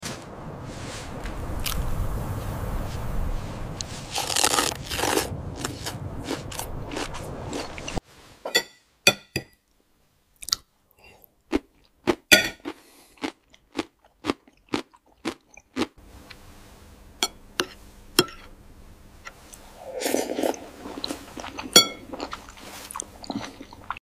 This silent ASMR journey follows a timeless leader through three stages of his life — a soldier, a founder, and a man in solitude. Each scene is told through food, silence, and simple gestures.
🔇 No talking, no music, no water 📽 Still camera, pure eating sounds, historical minimalism